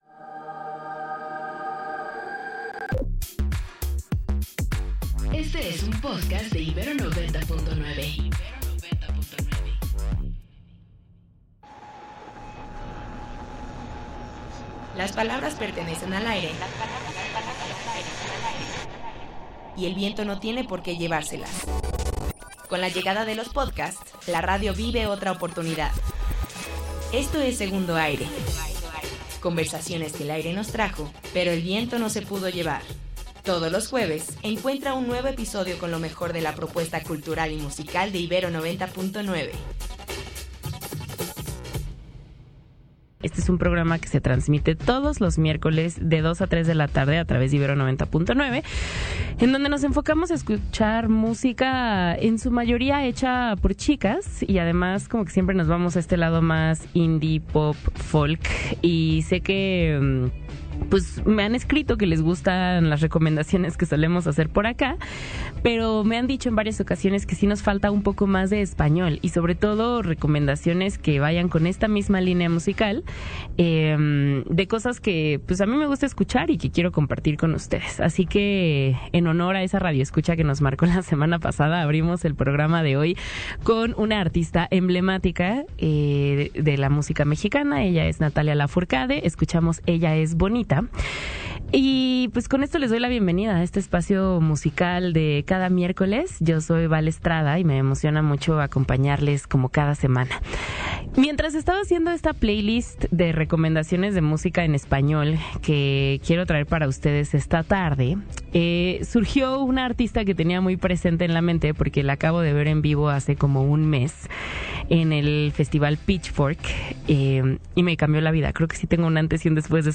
Entrevista con Silvana Estrada - 16.06.2025